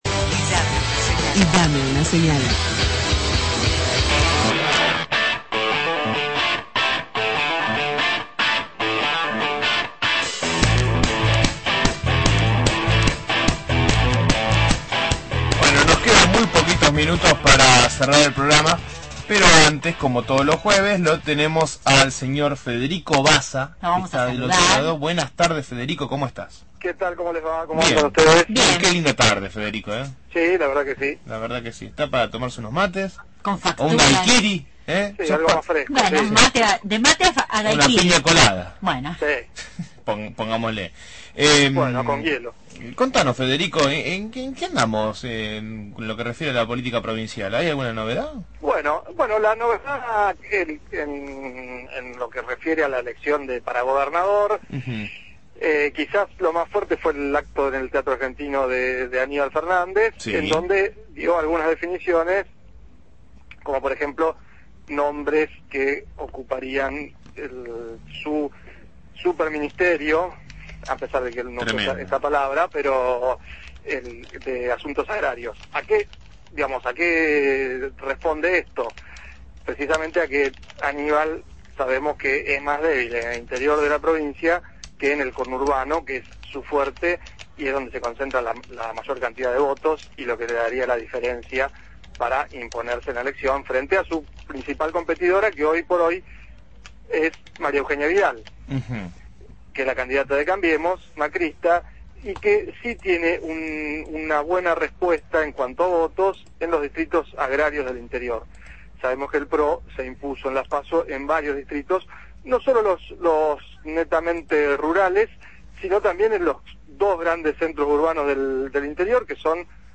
Columna semanal